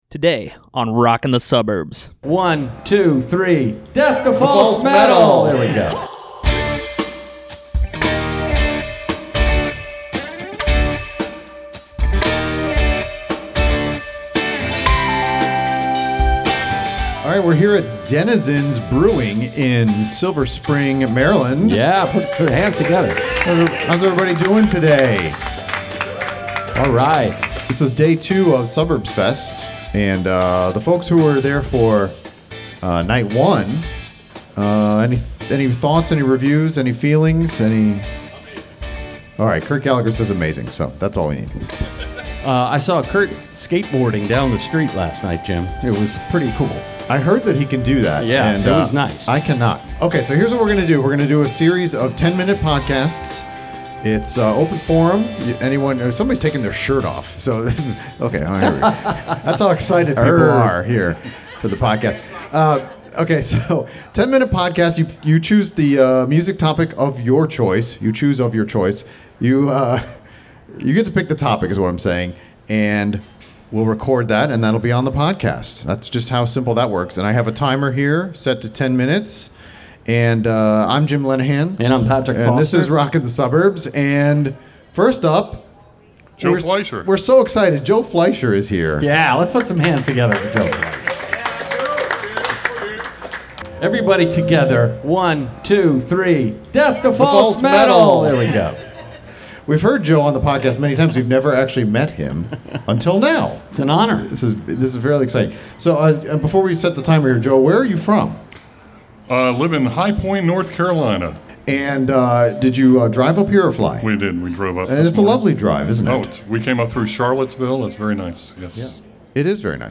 The first of a series of episodes recorded at Suburbs Fest DC, on location at Denizens Brewing Co.